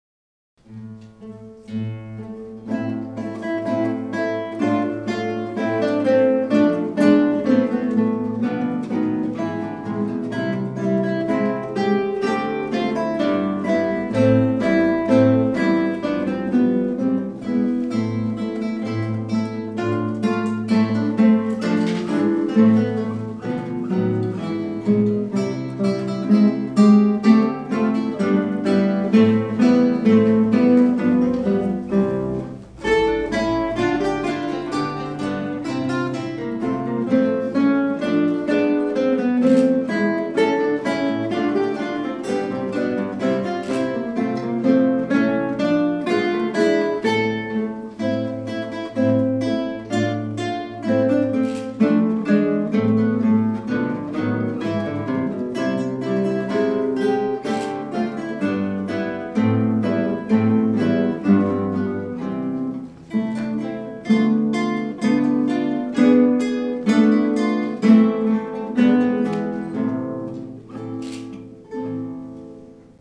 • Kytarový orchestr
Hráči používají klasické kytary ve standardním ladění, 1/8 kytary se sopránovými oktávovými strunami a klasickou basovou kytaru se strunami laděnými o oktávu níže, popř. sólovou elektrickou kytaru (u úprav rockových skladeb). Do některých skladeb bývají také zapojeny perkusní či Orffovy nástroje.